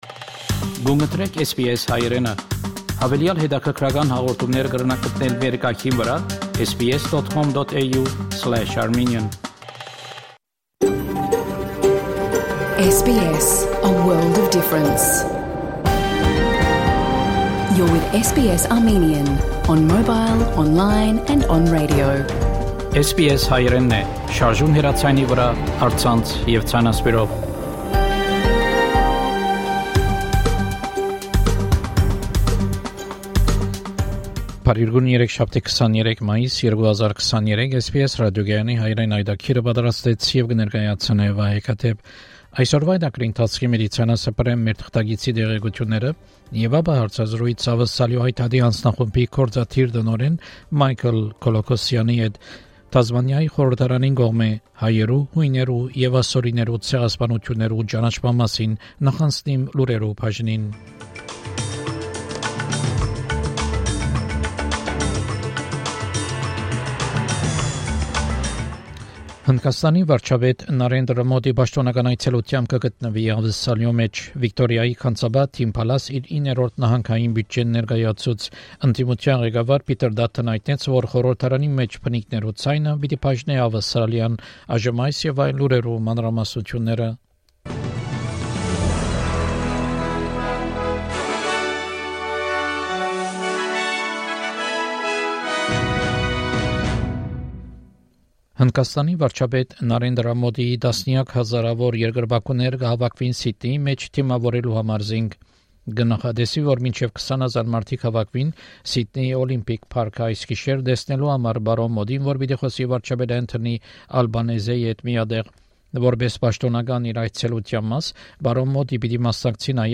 SBS Armenian news bulletin – 23 May 2023
SBS Armenian news bulletin from 23 May 2023 program.